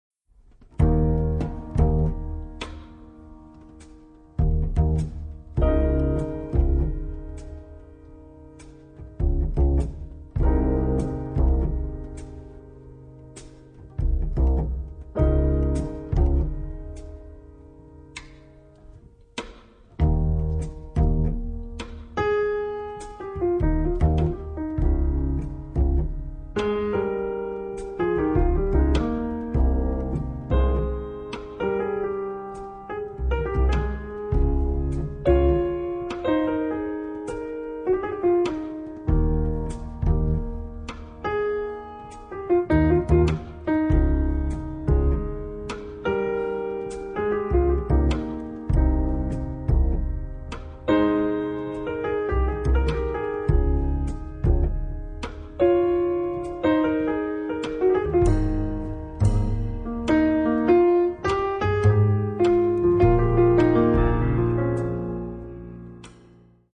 è un piano trio dei più classici